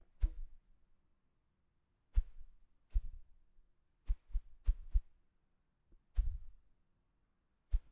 描述：苍蝇拍的棍子被某人的东西拍打
声道立体声